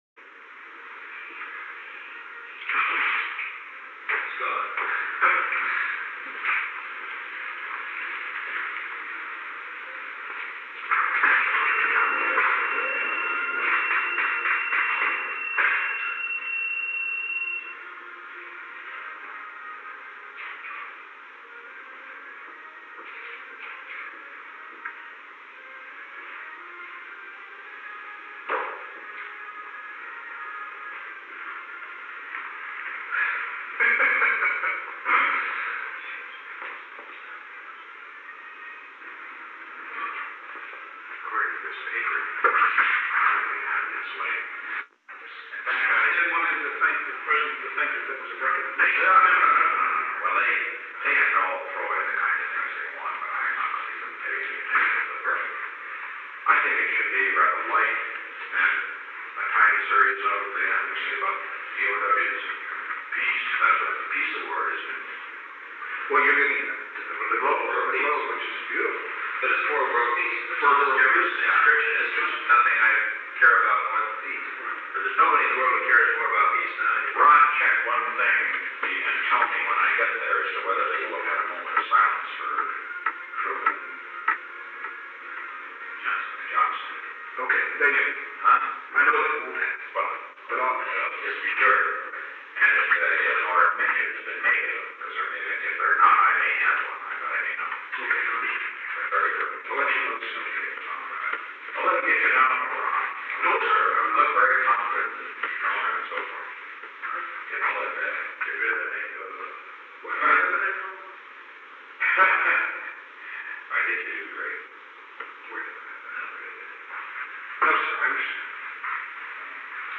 Conversation No. 896-5 Date: April 14, 1973 Time: Unknown between 2:13 pm between 3:55 pm Location: Oval Office H. R. (“Bob”) Haldeman met with an unknown man [John D. Ehrlichman ?].
Secret White House Tapes